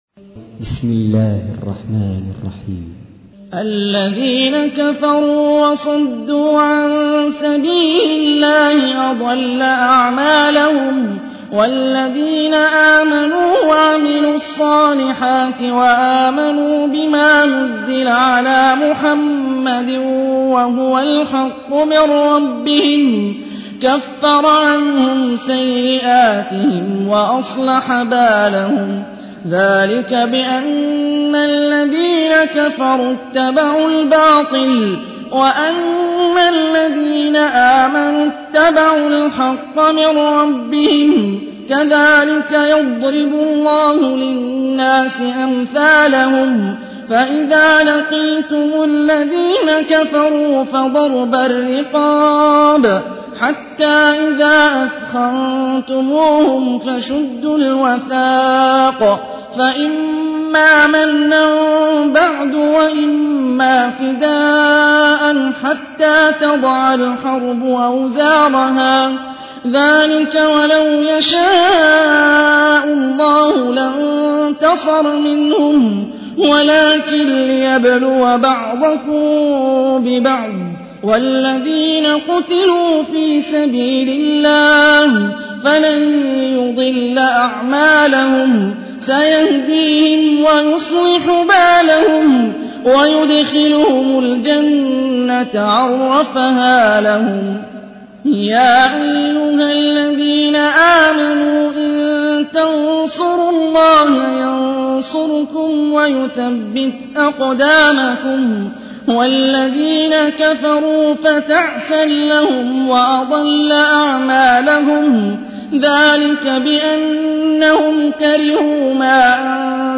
تحميل : 47. سورة محمد / القارئ هاني الرفاعي / القرآن الكريم / موقع يا حسين